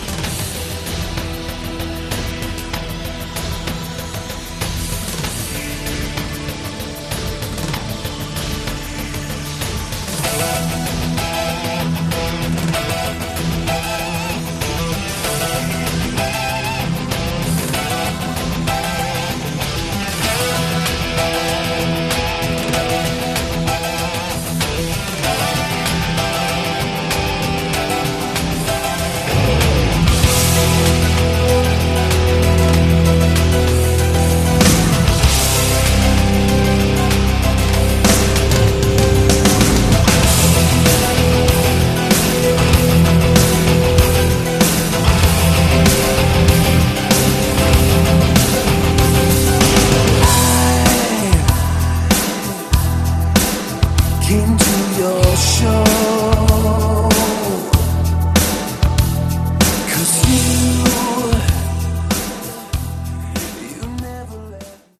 Category: AOR
vocals